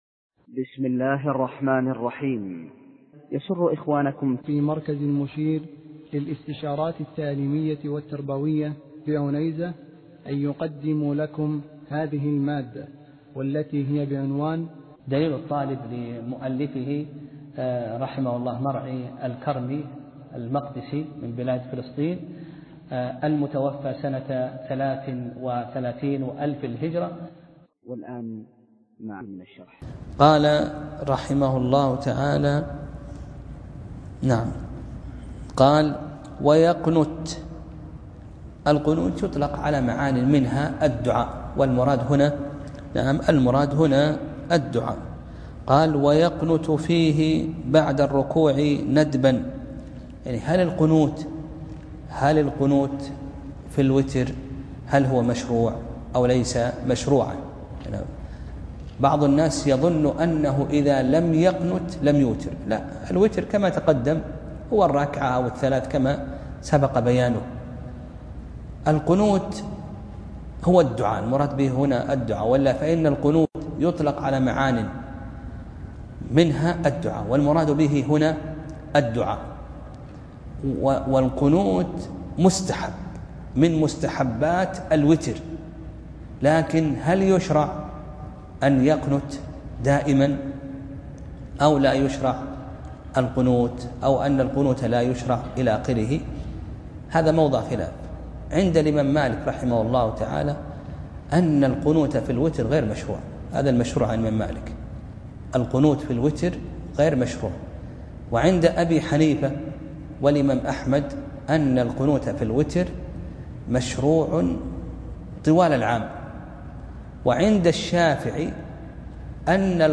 درس (4) : باب صلاة التطوع